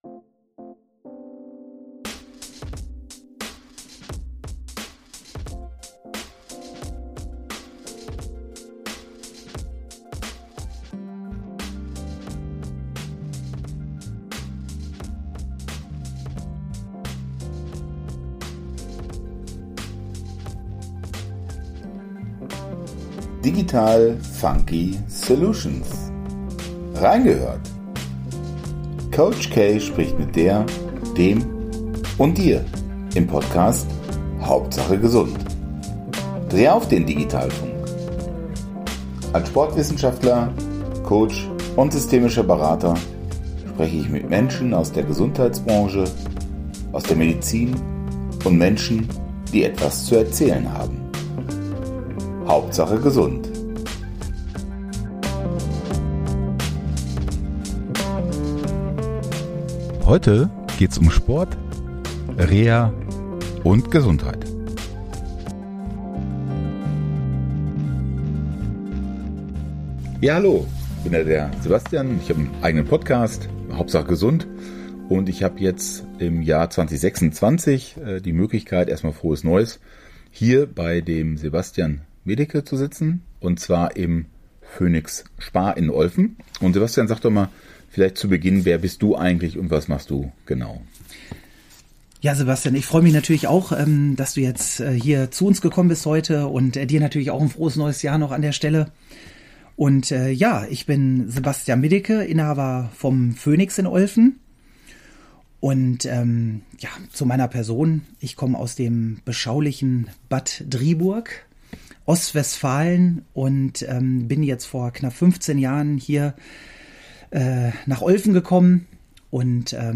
Hör rein in diesen stimmigen Dialog über die Themen Prävention, Gesundheit und Sport.